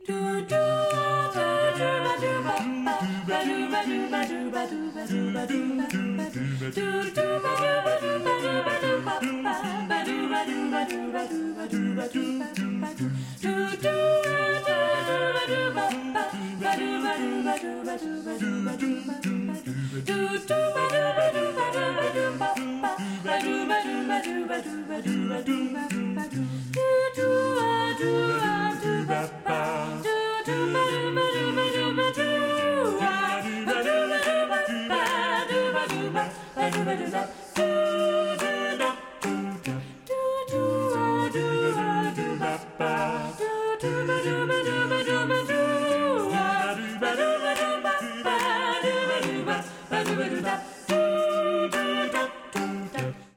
Wohnzimmerprobe am 20. Januar 2024